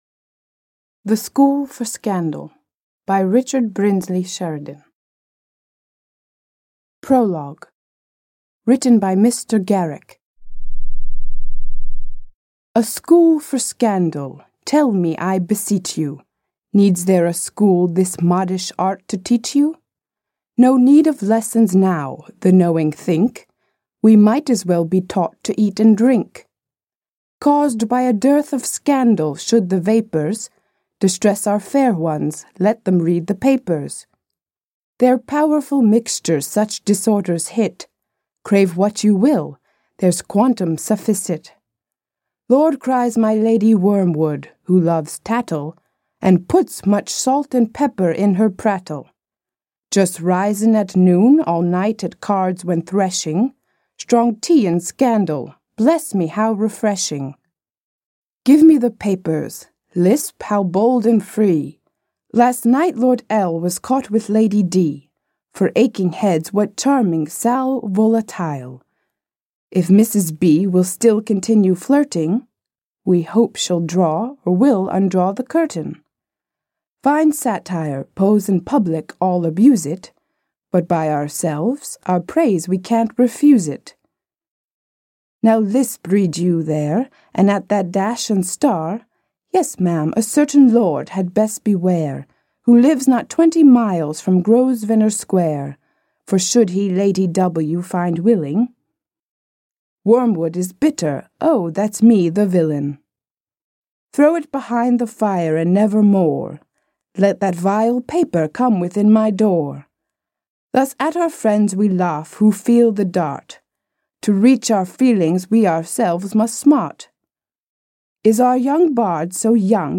Аудиокнига The School for Scandal | Библиотека аудиокниг